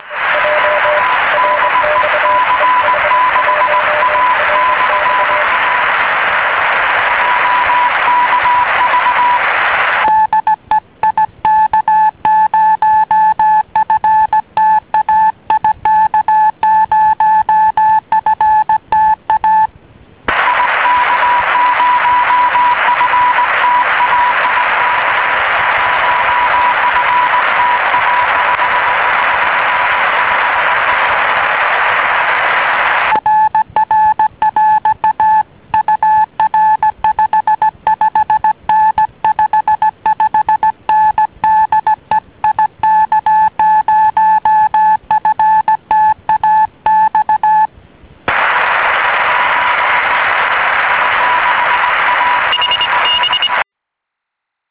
Hear His Signal in Rome!